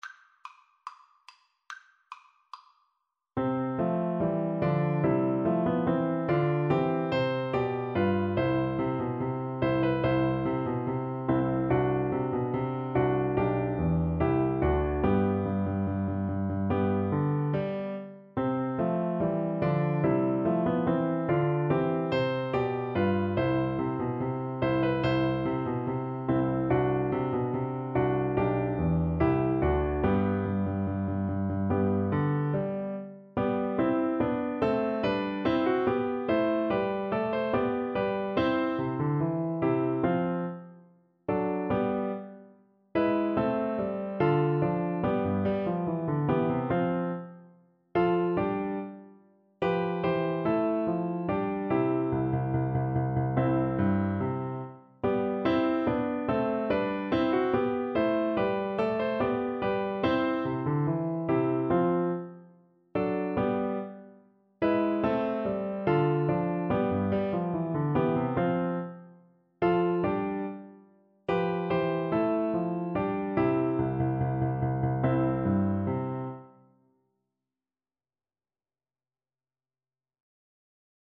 Animato = 144
Classical (View more Classical Oboe Music)